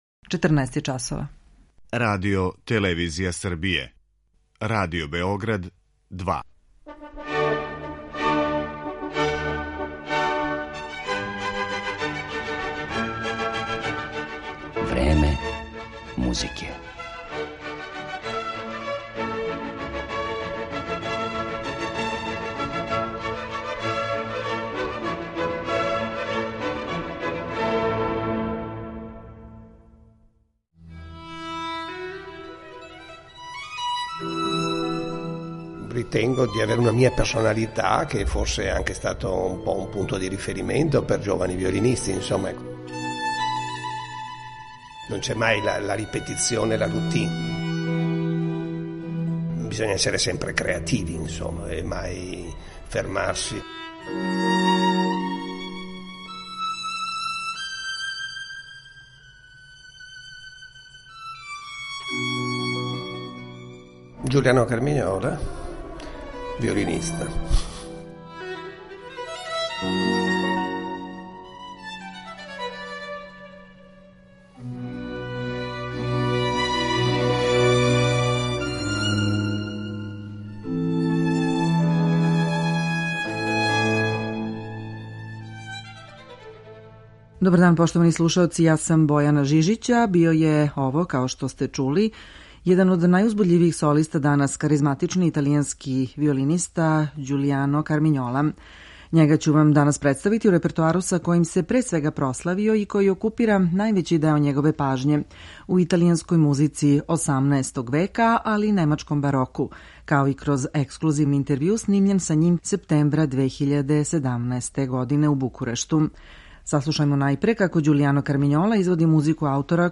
Ovog izuzetnog i neobičnog umetnika predstavićemo u repertoaru sa kojim se pre svega proslavio i koji okupira najveći deo njegove pažnje - u italijanskoj muzici 18. veka, kao i kroz ekskluzivni intervju.